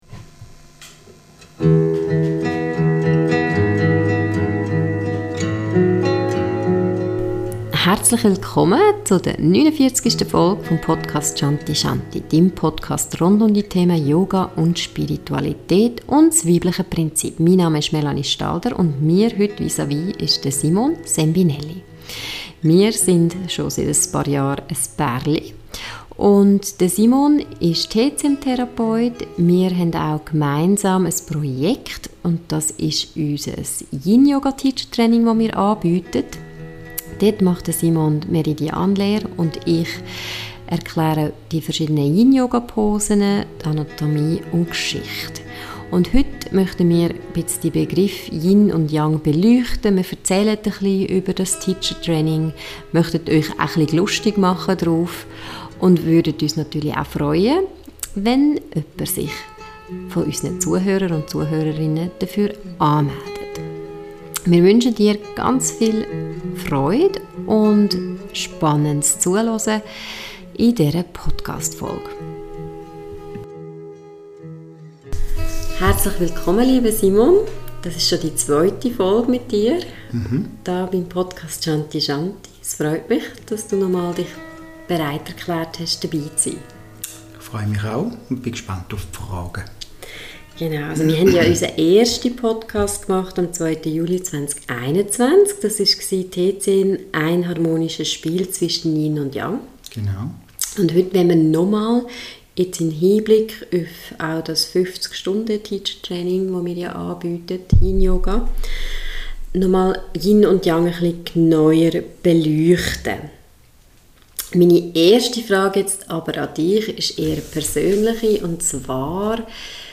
49: YIN Yoga besser verstehen – Interview